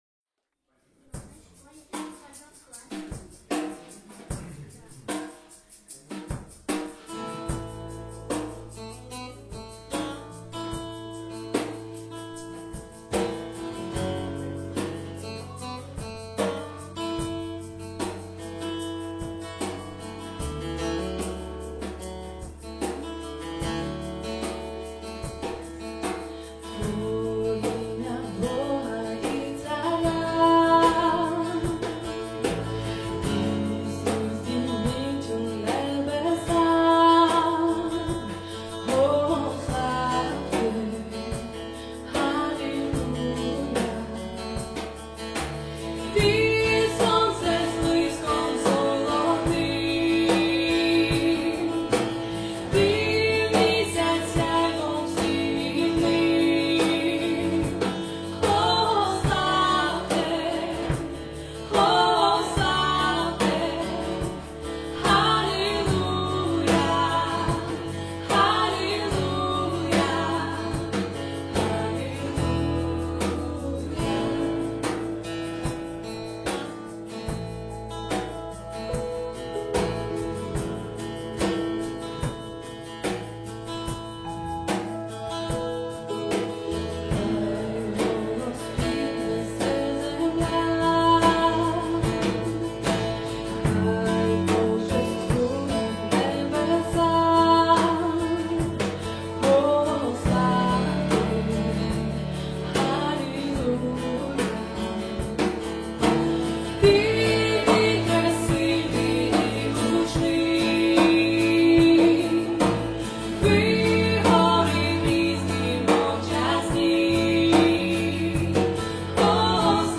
69 просмотров 52 прослушивания 0 скачиваний BPM: 75